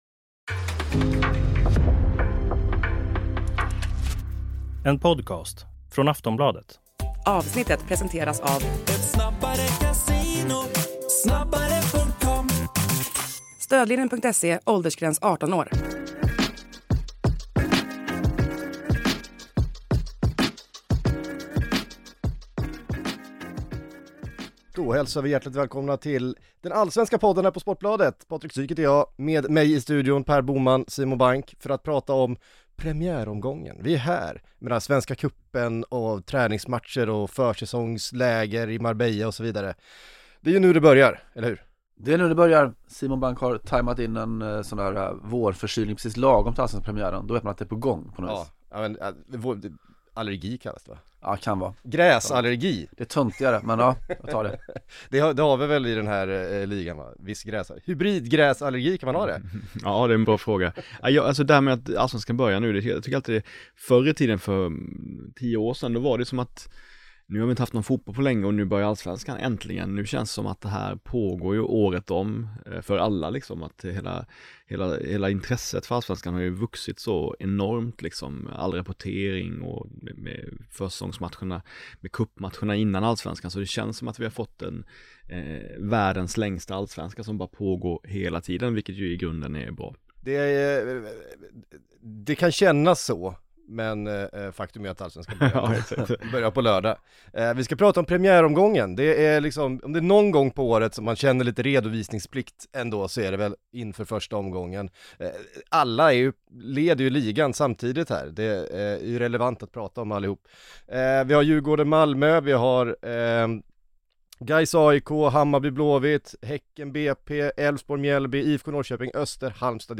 i poddstudion för att syna den första vibrerande omgången av allsvenskan 2025.